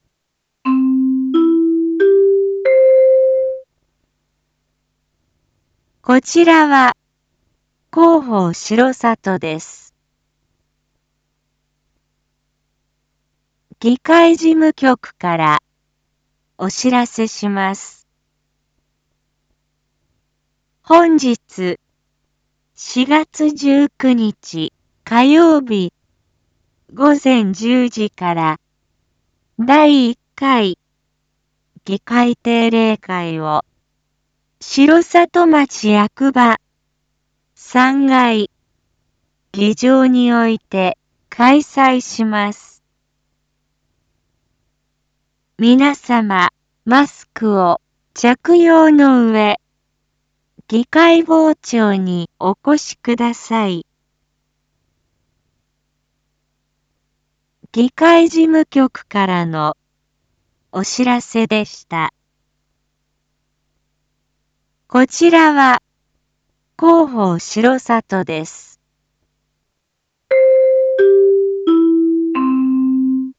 一般放送情報
Back Home 一般放送情報 音声放送 再生 一般放送情報 登録日時：2022-04-19 07:01:19 タイトル：R4.4.19 7時放送分 インフォメーション：こちらは広報しろさとです。